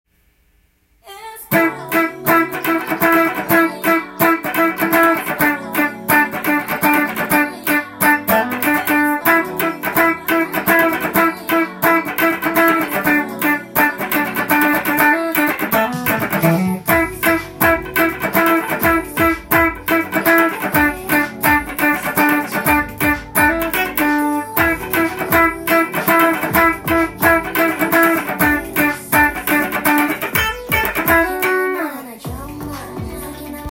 音源に合わせて譜面通り弾いてみました
かなりオシャレな響きがするダンスナンバーです。
覚えやすいカッティングになっています。